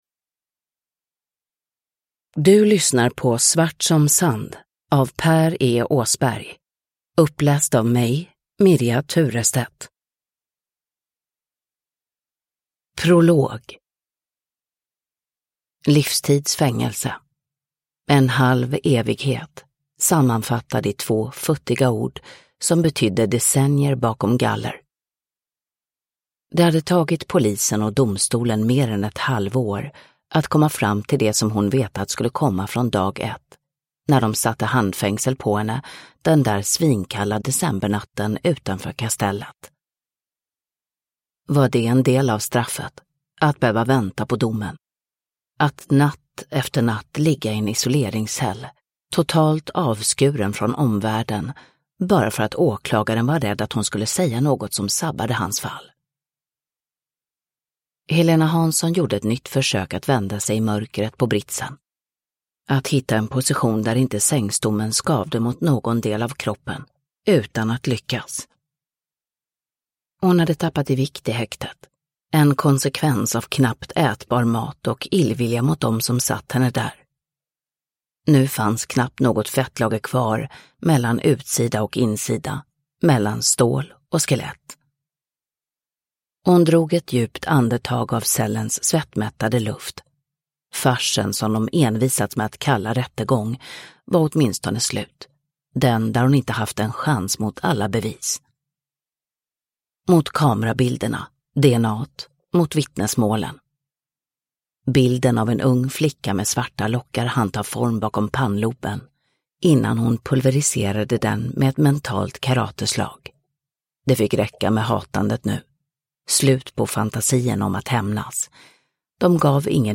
Svart som sand (ljudbok) av Per E Åsberg